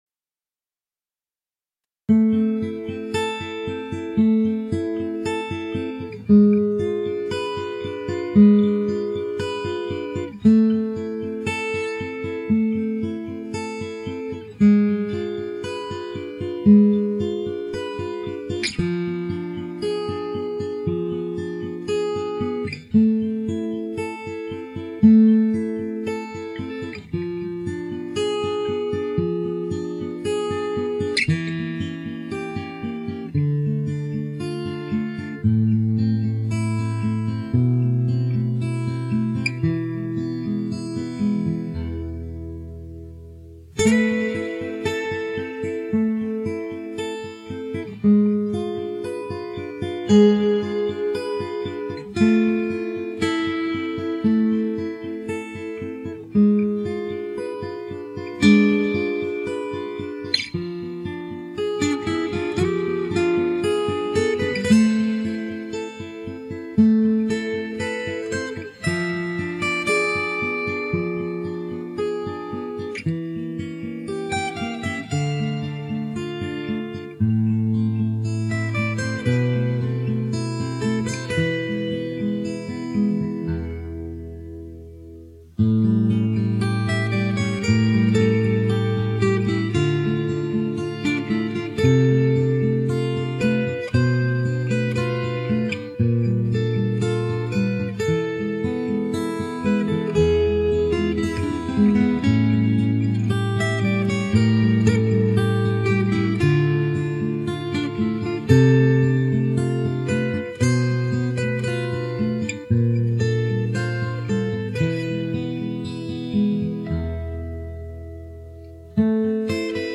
Гитары
(instrumental)